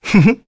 Neraxis-Vox-Laugh_kr.wav